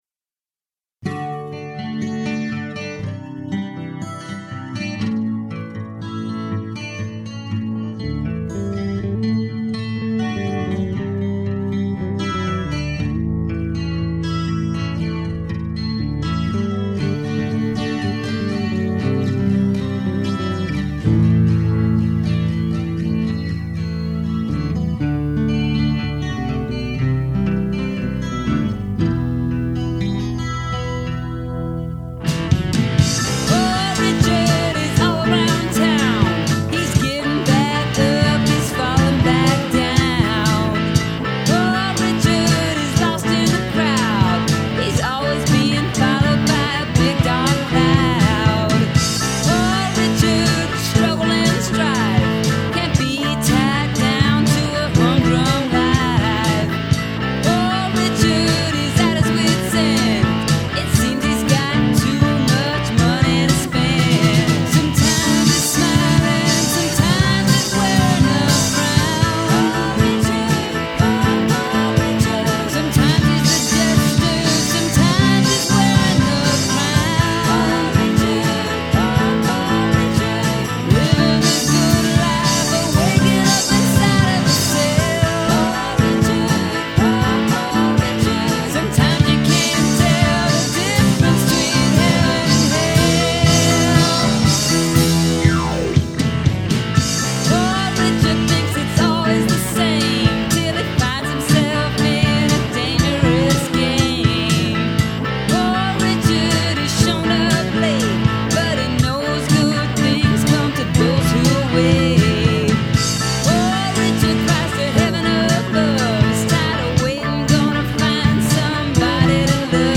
Vocals
Guitars
Bass / Drums / Keyboard